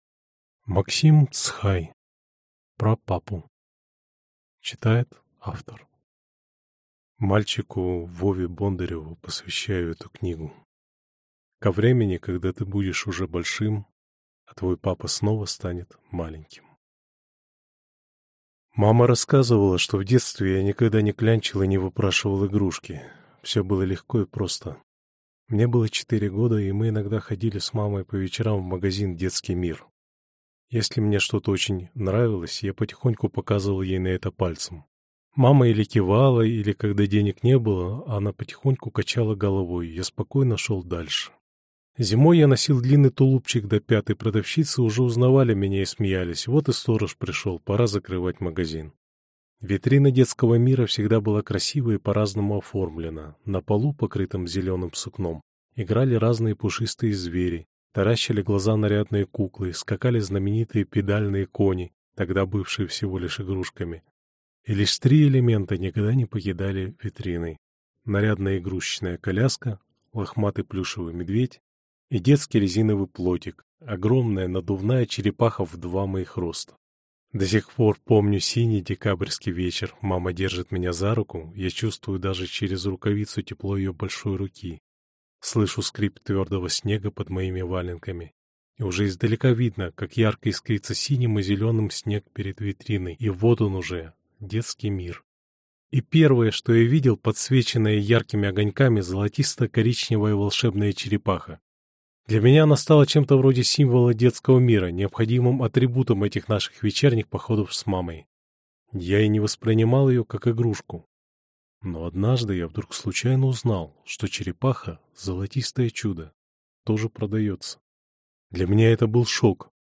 Аудиокнига Про папу | Библиотека аудиокниг